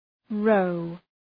Προφορά
{rəʋ}
row.mp3